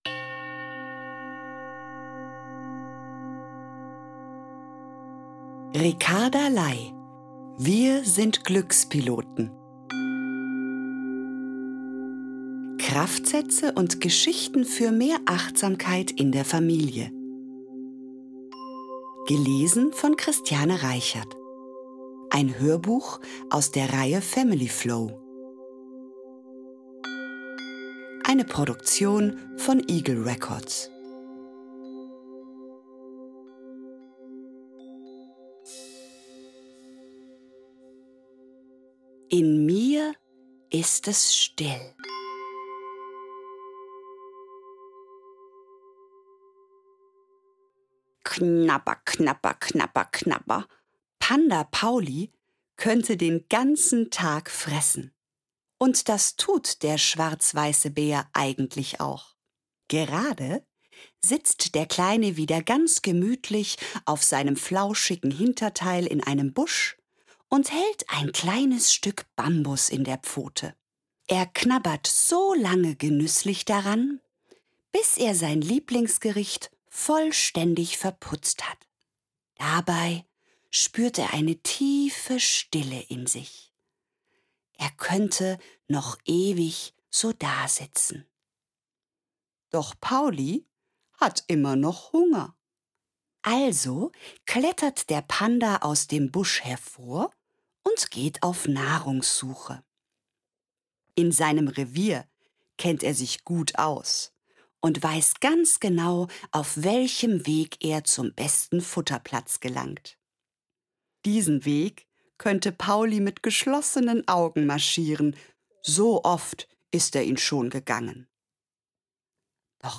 Hörbuch: FamilyFlow.